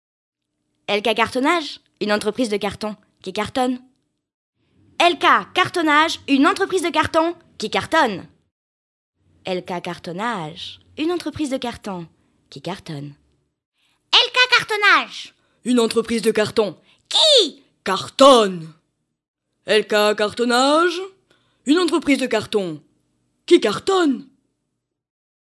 Sprecherin französisch.
Sprechprobe: Industrie (Muttersprache):